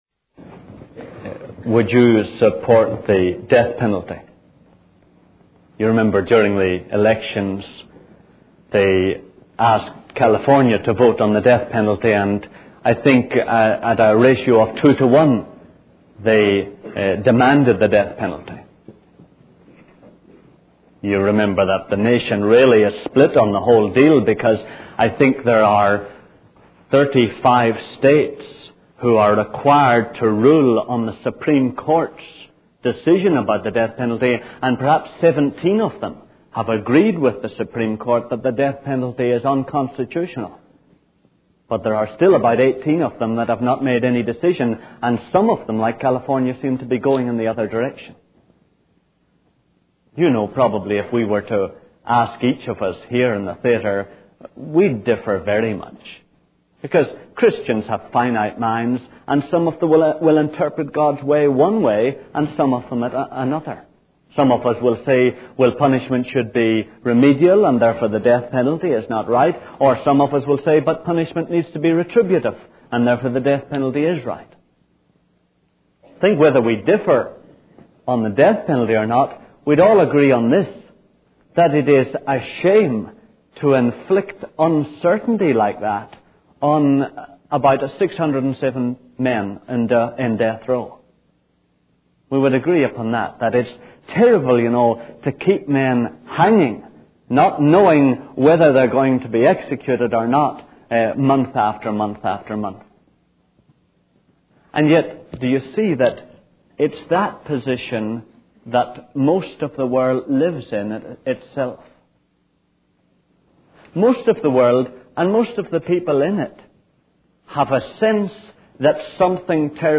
In this sermon, the speaker discusses the presence of evil and suffering in the world and how it challenges the belief in a perfect future. He highlights the destruction, violence, and injustice that occur, causing a strain on the spirit.